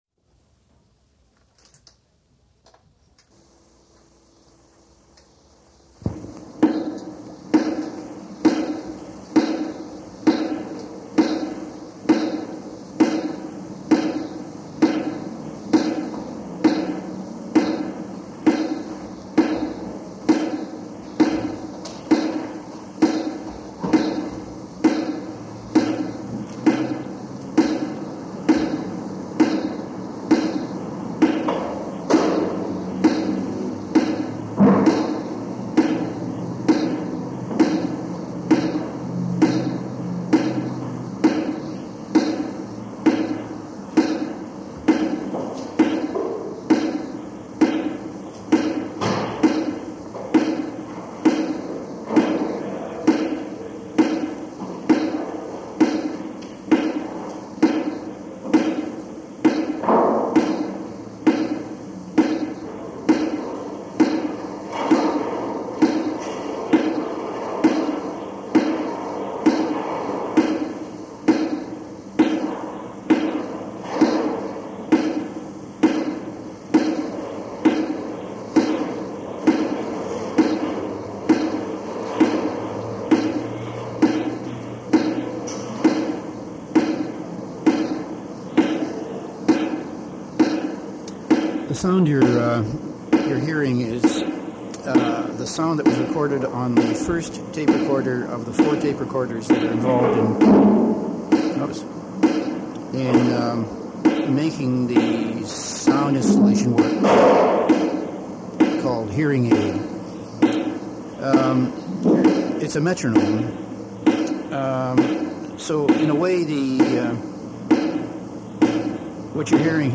03 Discussion_ Hearing Aid.mp3